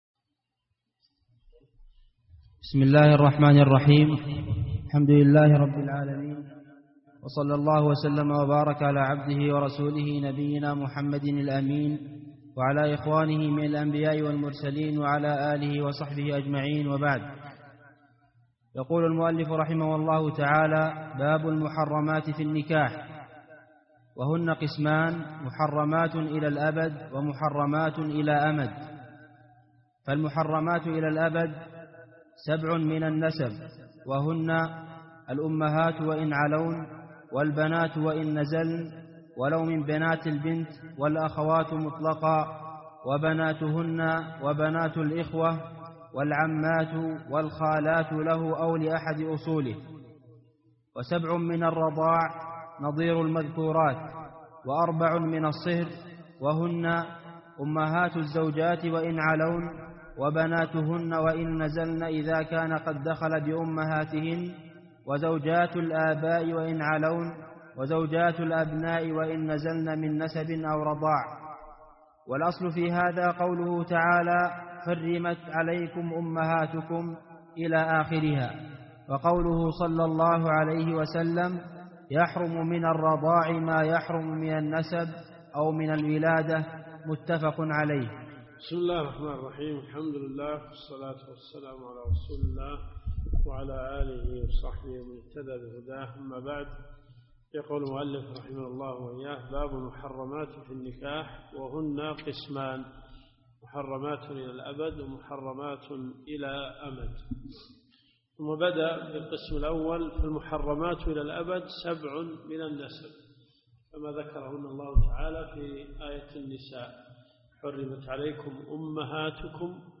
الدروس الشرعية
منهج السالكين . كتاب النكاح من ص 89 باب المحرمات في النكاح -إلى- ص 96 قوله ( .... فحرام عليها رائحة الجنة ) . المدينة المنورة . جامع البلوي